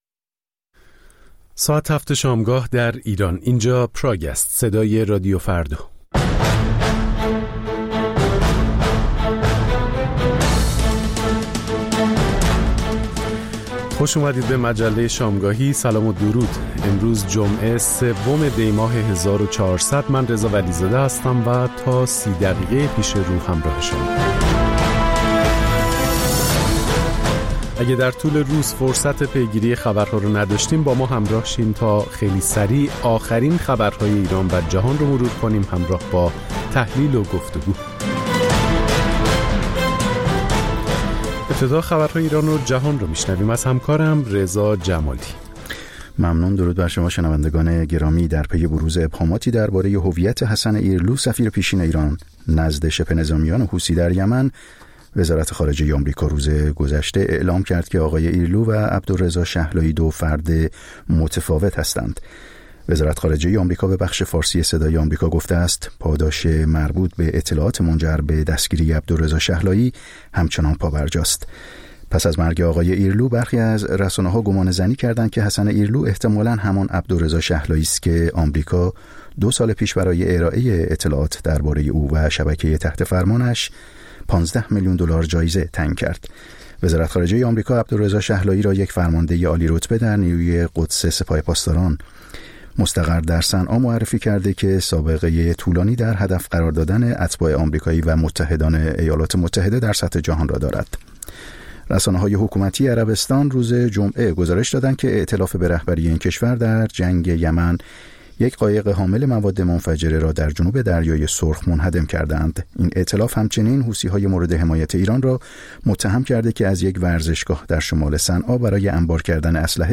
مجموعه‌ای متنوع از آنچه در طول روز در سراسر جهان اتفاق افتاده است. در نیم ساعت اول مجله شامگاهی رادیو فردا، آخرین خبرها و تازه‌ترین گزارش‌های تهیه‌کنندگان رادیو فردا پخش خواهد شد.